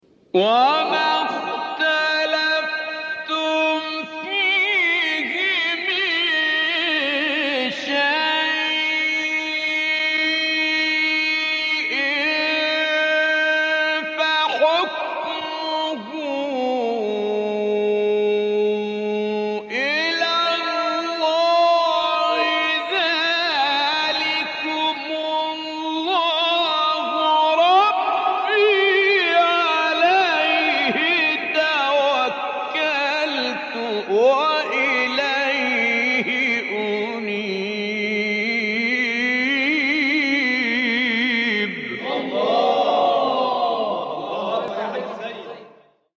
آیه 10 سوره شوری استاد متولی عبدالعال | نغمات قرآن | دانلود تلاوت قرآن